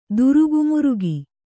♪ durugumurugi